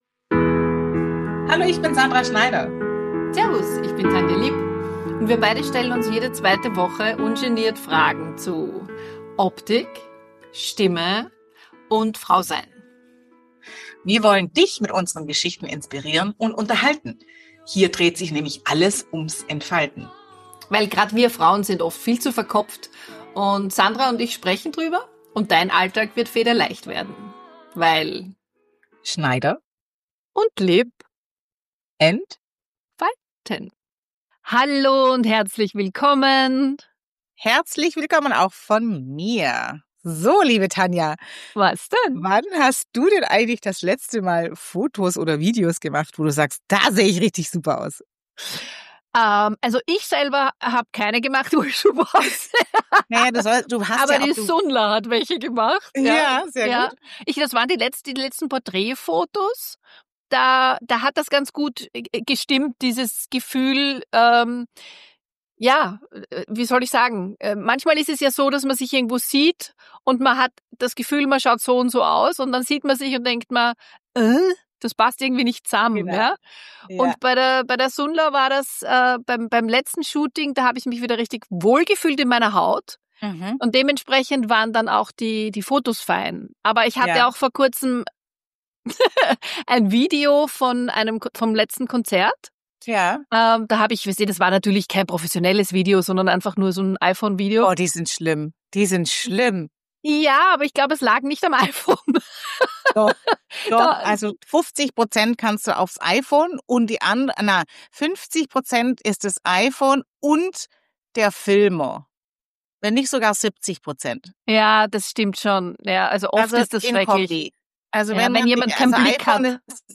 Ein ehrliches, charmantes Gespräch über Wahrnehmung, kleine Eitelkeiten und das gute Gefühl, wenn Bild, Stimme und Selbstwahrnehmung endlich zusammenpassen.